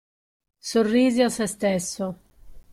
Read more oneself, himself, herself Frequency B2 Pronounced as (IPA) /ˈse/ Etymology From Latin sē.